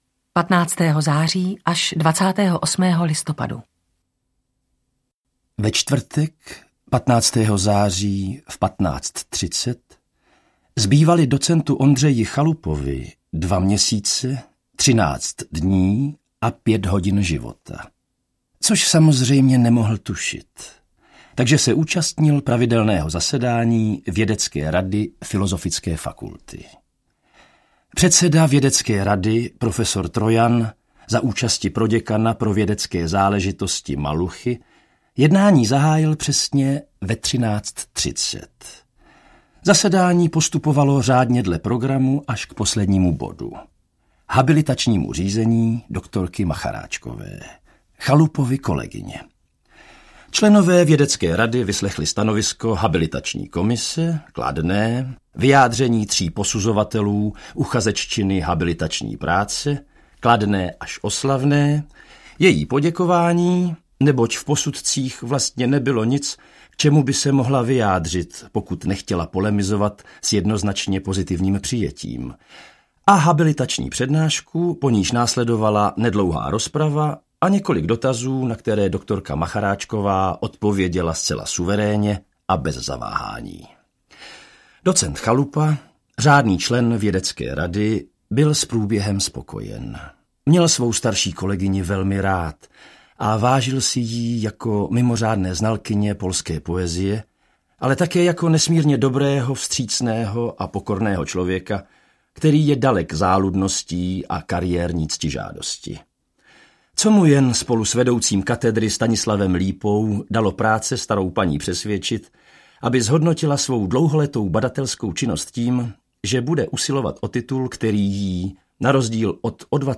Modré stíny audiokniha
Ukázka z knihy